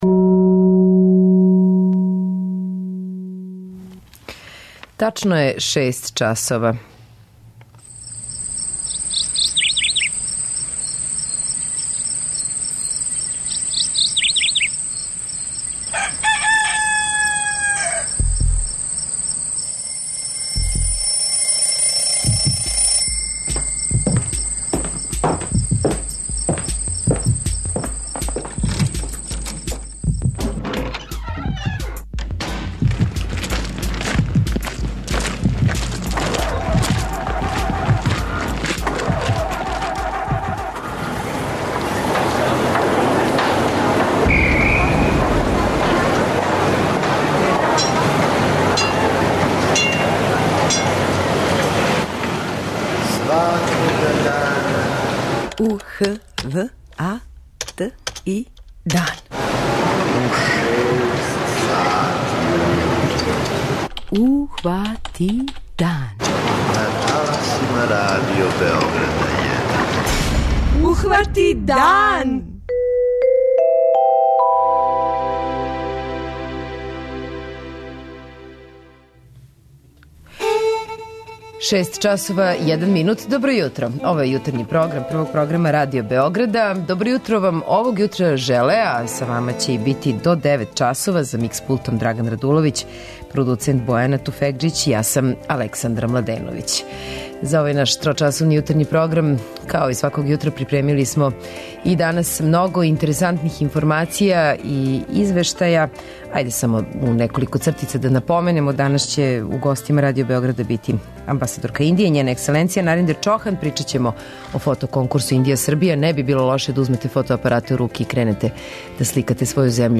Гошћа јутарњег програма биће Амбасадор Индије у Београду, Њена Екселенција Нариндер Чохан, са којом ћемо разговарати о Фото конкурсу Индија - Србија 2014, који је отворен 10. јула.